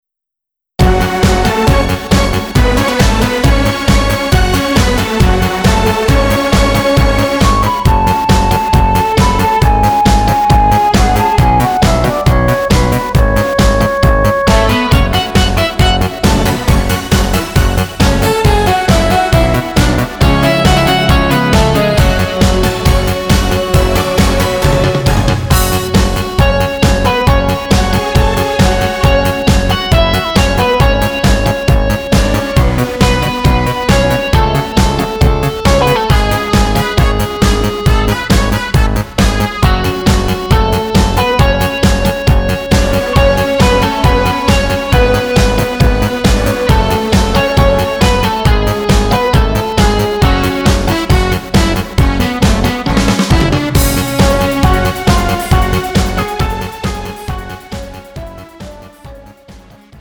음정 (-1키)
장르 가요 구분 Pro MR